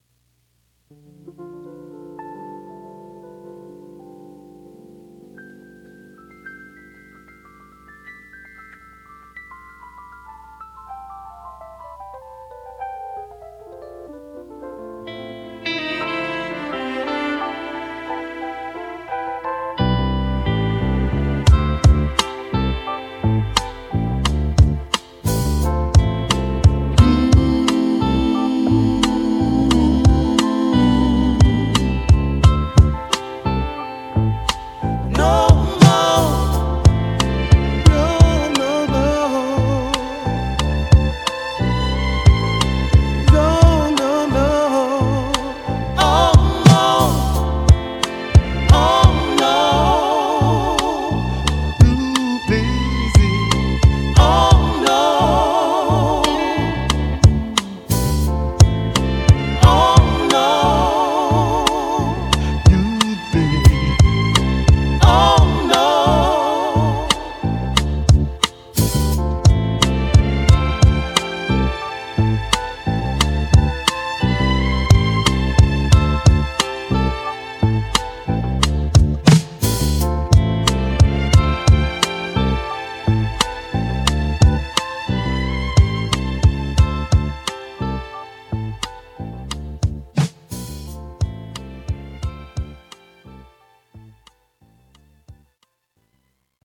royalty-free sample pack